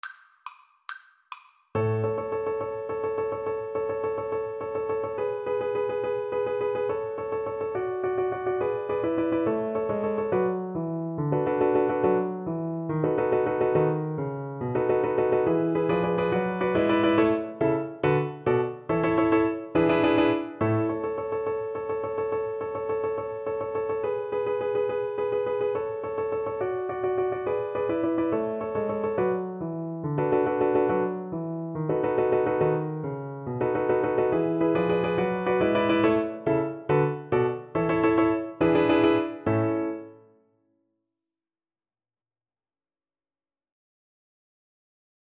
Play (or use space bar on your keyboard) Pause Music Playalong - Piano Accompaniment Playalong Band Accompaniment not yet available transpose reset tempo print settings full screen
6/8 (View more 6/8 Music)
A major (Sounding Pitch) (View more A major Music for Cello )
Allegro Vivace .=140 (View more music marked Allegro)
Classical (View more Classical Cello Music)